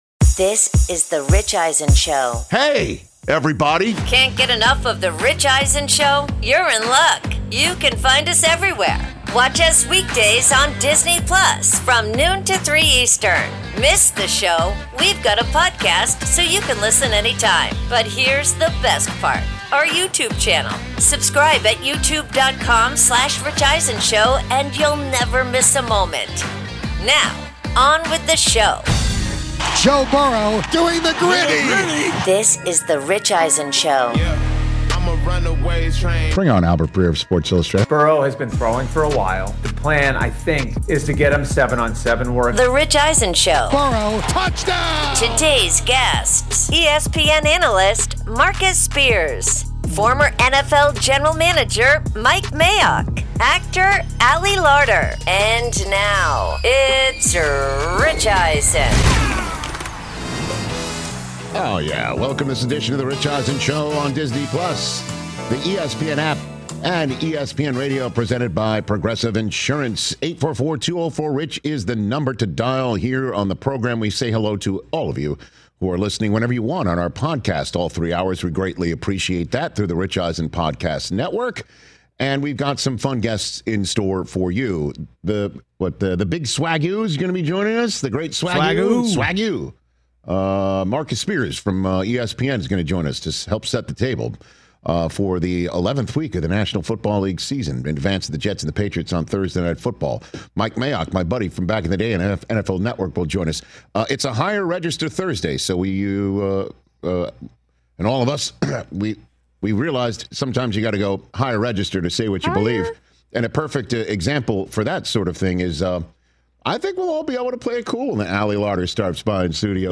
ESPN NFL analyst Marcus Spears and Rich discuss how the Philadelphia Eagles keep winning games despite their internal dysfunction, says if the Patriots, Broncos, Colts, Chargers, Bills, Ravens, Chiefs and Steelers are legit Super Bowl contenders, and breaks down the top teams that could rep the NFC on Super Sunday,